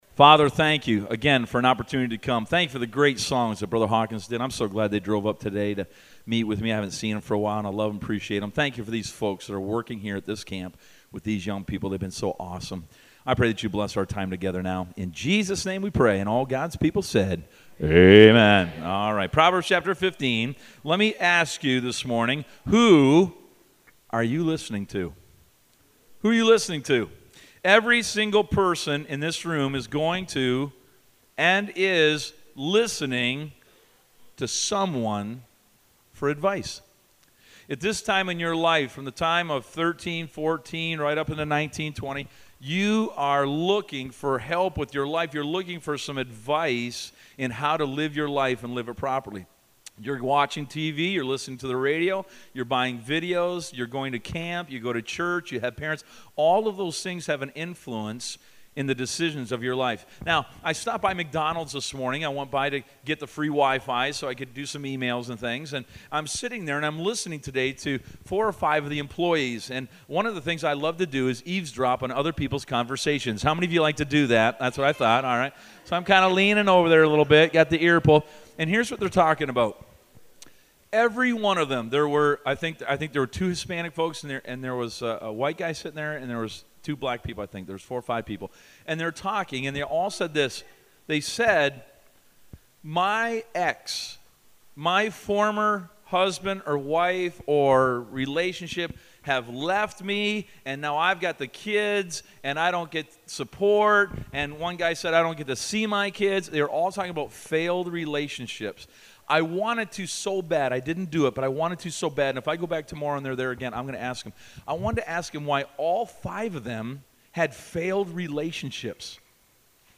Listen to Message
Service Type: Teen Camp